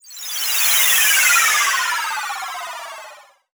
008_MAGENTAMELONFX.wav